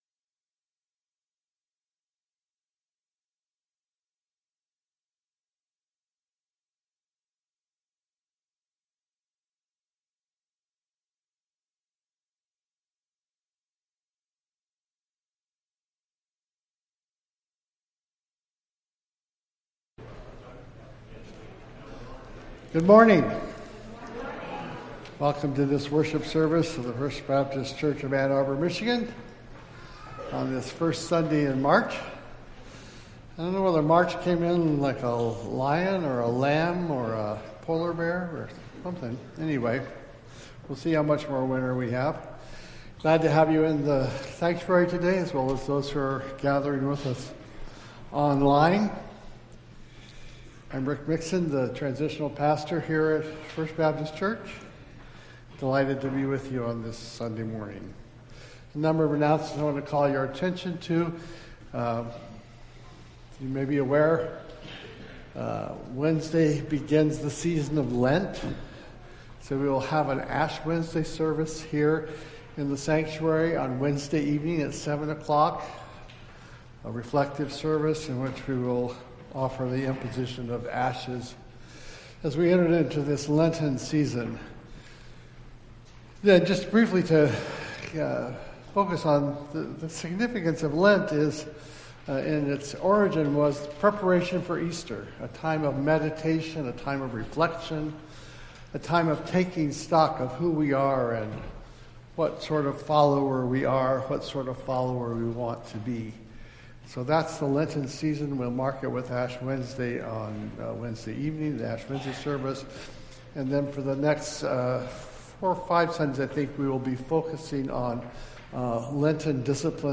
Entire March 2nd Service